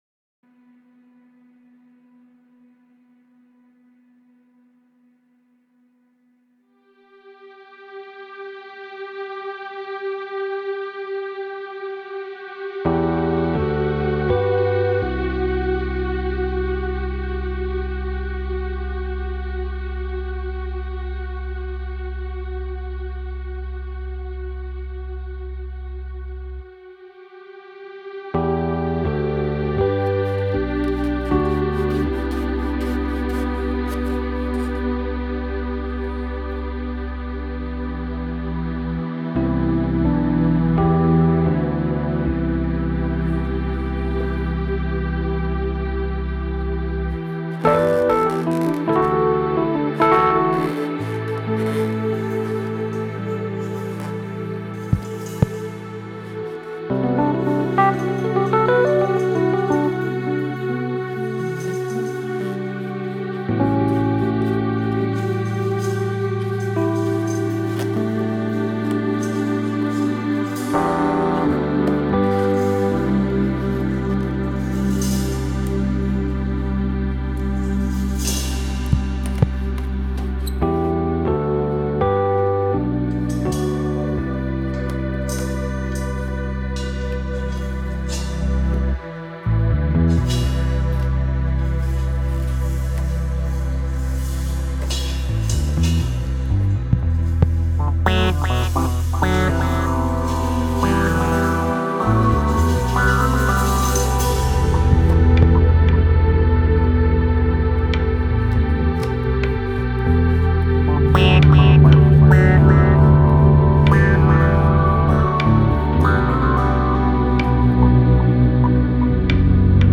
piano, organ, yamaha tx7, korg sm 2000